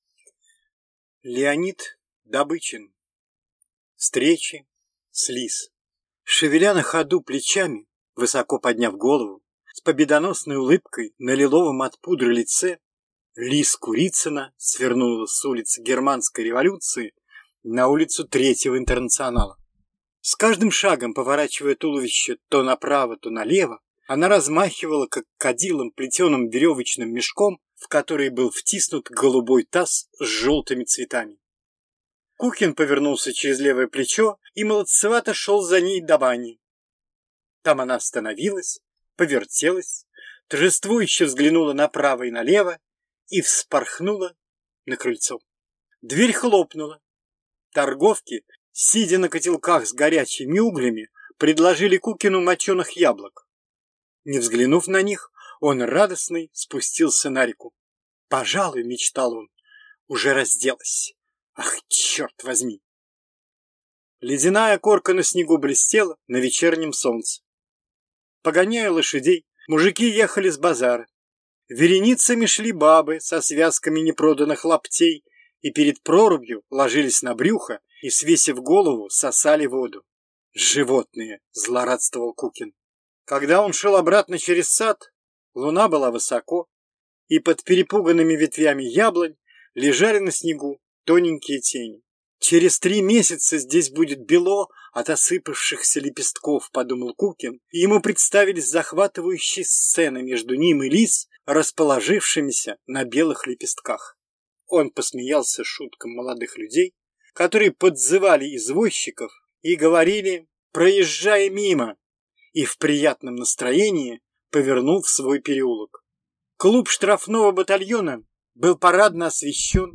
Аудиокнига Встречи с Лиз | Библиотека аудиокниг